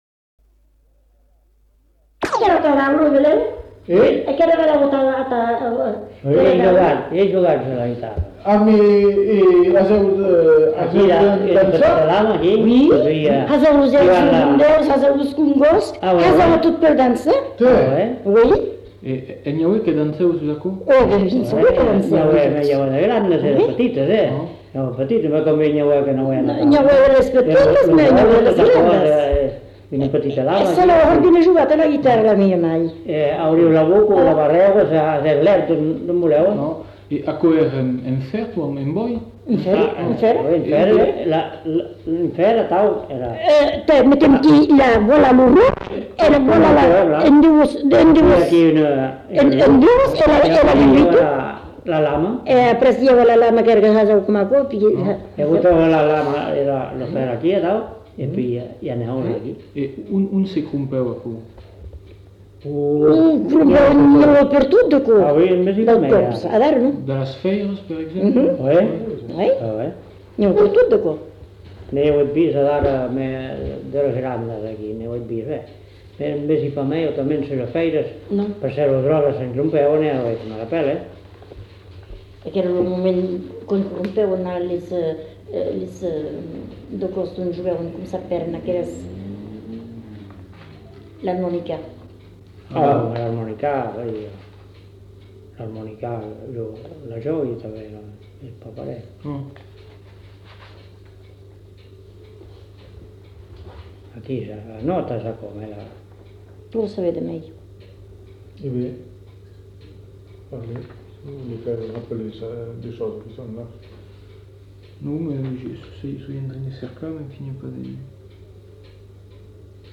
Aire culturelle : Bazadais
Lieu : Cazalis
Genre : témoignage thématique
Instrument de musique : guimbarde